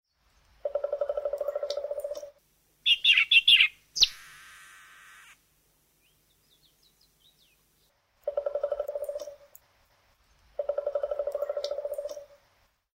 Stimme Buntspecht
Specht.mp3